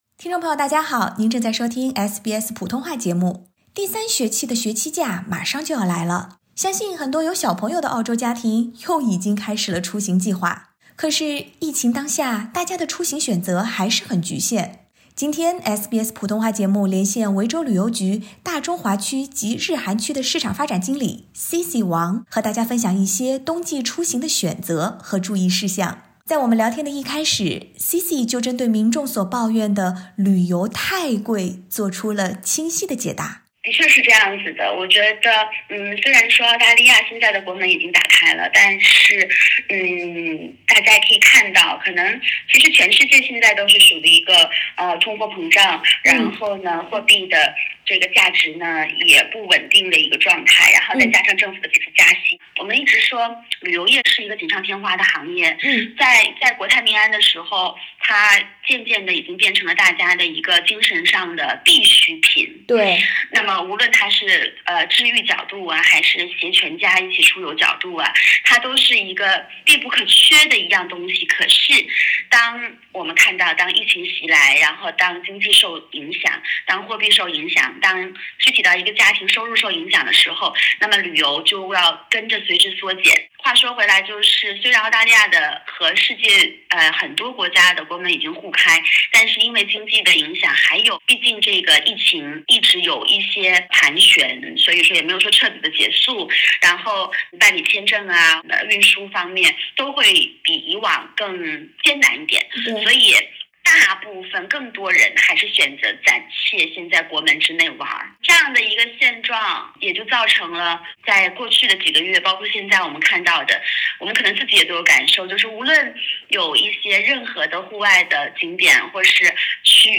采访最后